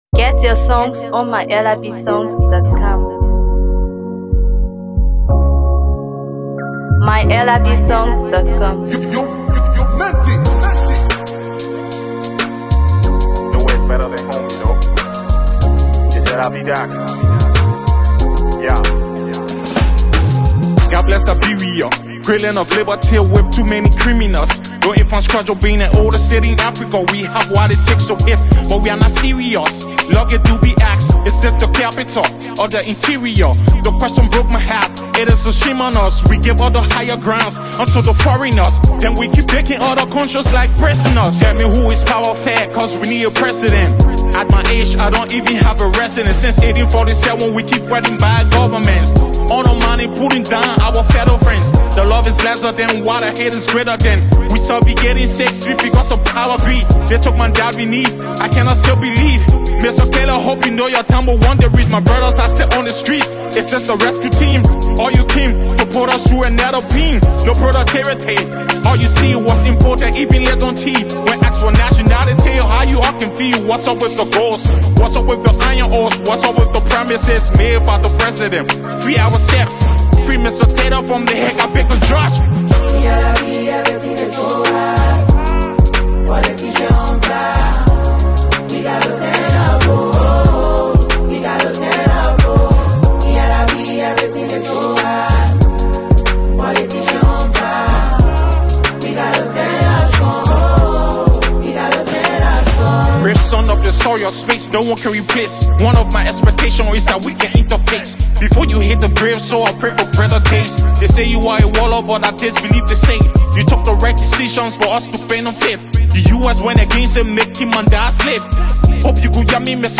Hip HopMusic
Promising Liberian rap artist